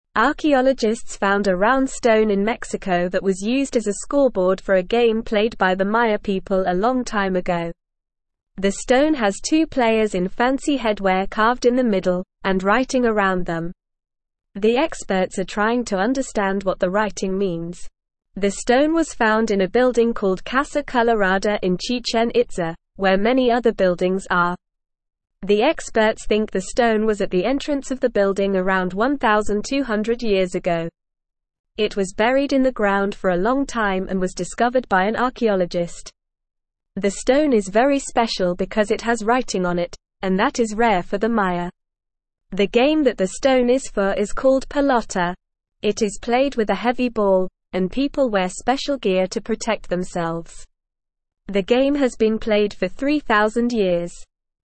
Normal
English-Newsroom-Beginner-NORMAL-Reading-Stone-Scoreboard-Found-in-Mexico.mp3